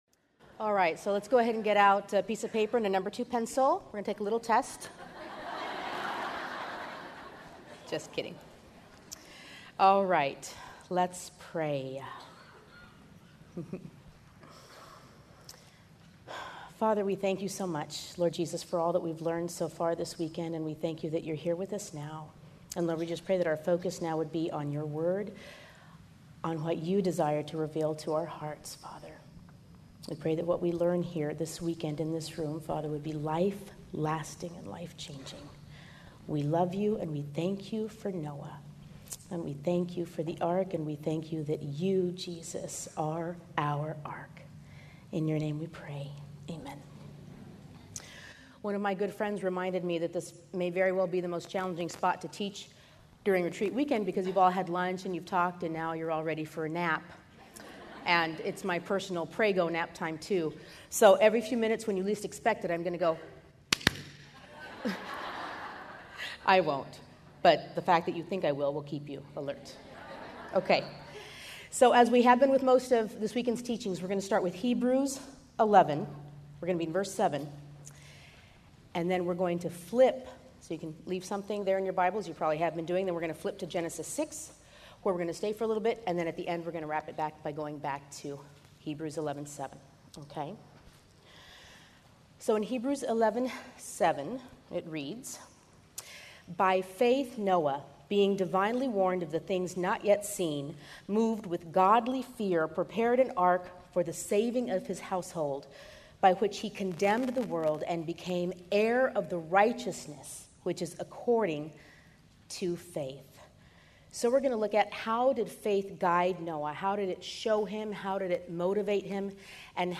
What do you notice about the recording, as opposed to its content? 2012 Women's Retreat